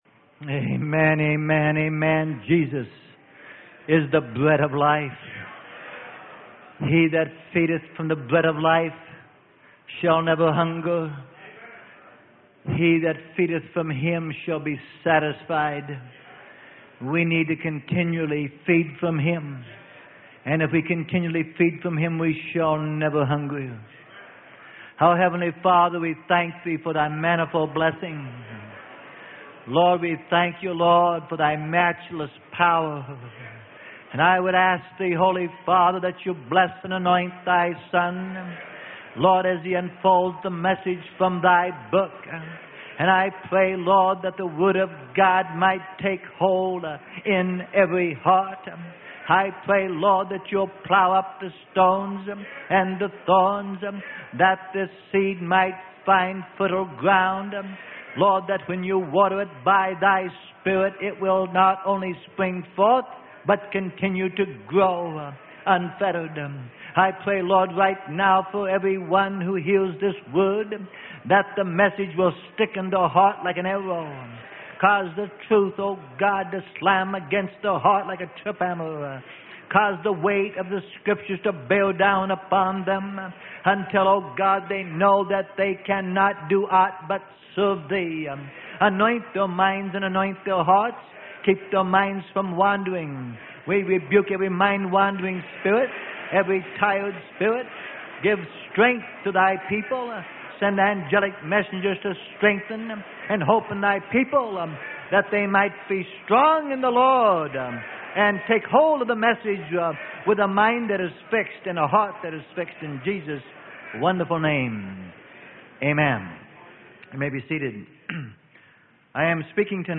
Sermon: BUILDING AND PERFECTING GOD'S CHURCH ACCORDING TO THE BLUEPRINT - PART 1 - Freely Given Online Library